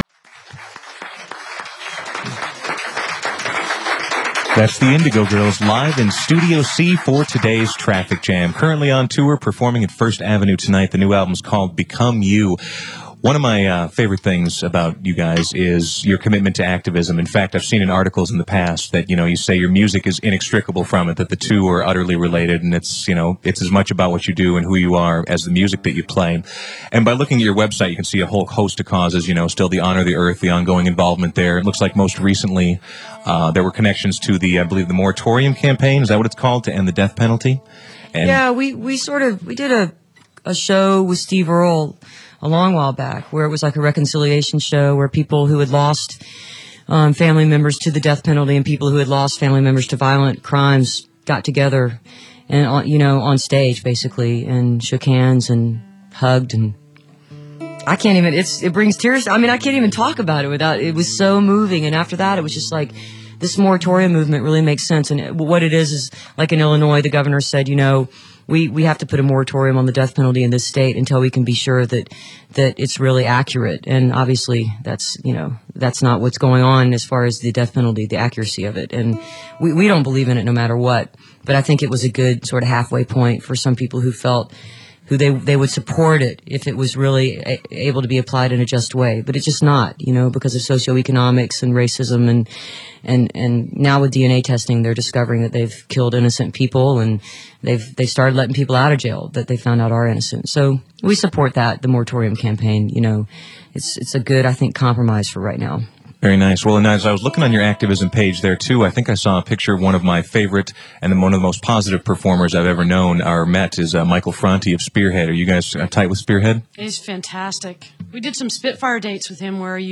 06. interview (2:58)